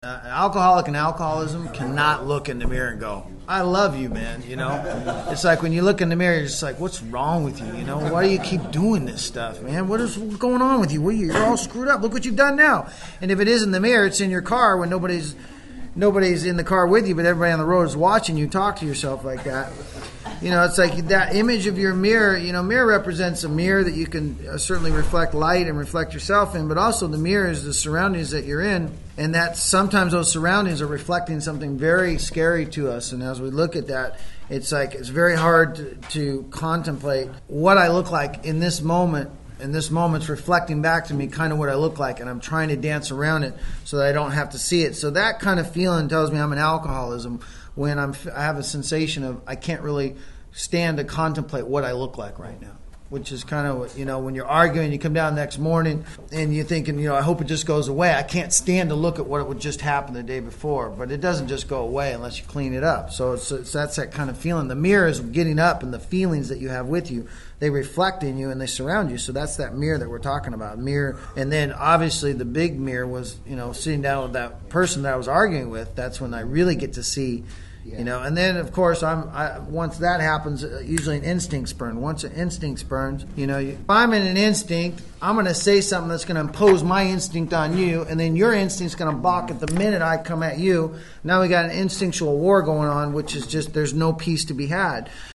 This audio archive is a compilation of many years of lecturing.
Within the lectures, you will hear people ask questions about why am I where I am, how can I get to a better place and what is blocking me.
This group discussion also highlights real-life experiences of conflict, resentment, and fear, illustrating how these moments reveal the disease at work.